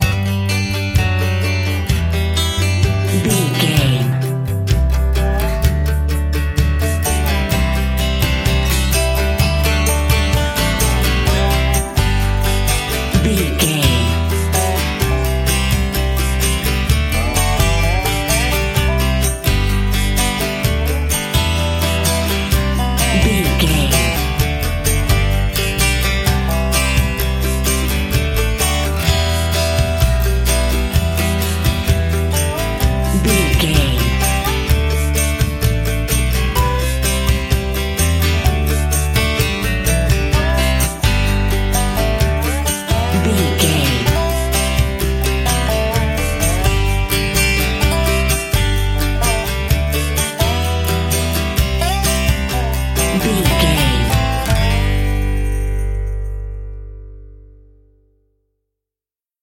Ionian/Major
acoustic guitar
bass guitar
banjo
Pop Country
country rock
bluegrass
happy
uplifting
driving
high energy